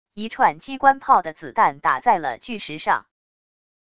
一串机关炮的子弹打在了巨石上wav音频